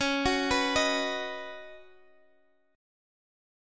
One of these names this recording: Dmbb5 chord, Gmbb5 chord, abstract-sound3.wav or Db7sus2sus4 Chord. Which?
Db7sus2sus4 Chord